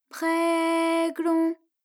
ALYS-DB-003-FRA - Source files of ALYS’ first publicly available French vocal library, initially made for Alter/Ego.